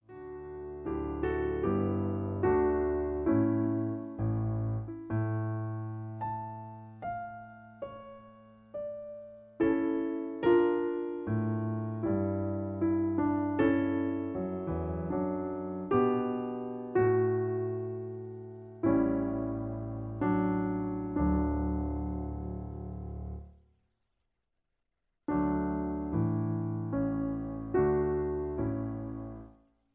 In D. Piano Accompaniment